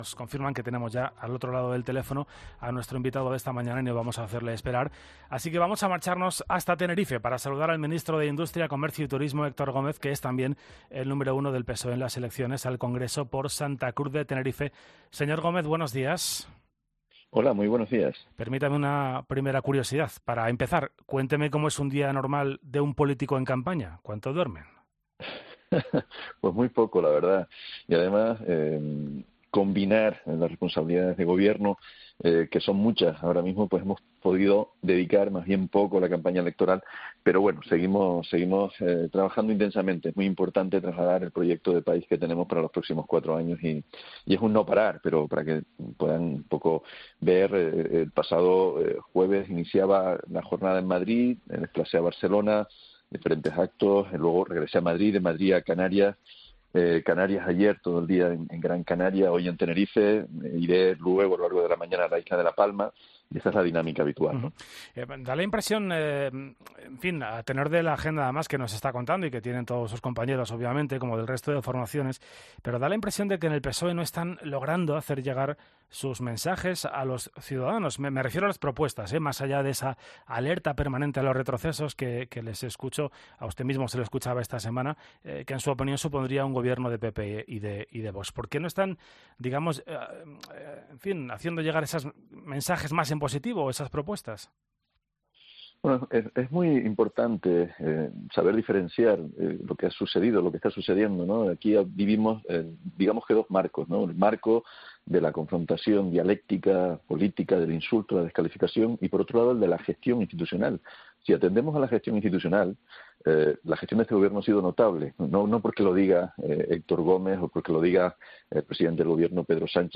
El Ministro de Industria, Comercio y Turismo y número uno del PSOE al Congreso por Santa Cruz de Tenerife, Héctor Gómez, ha pasado este sábado por los micrófonos de COPE para analizar la campaña electoral que está llevando a cabo su partido a menos de una semana para las elecciones generales.